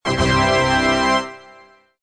tada.mp3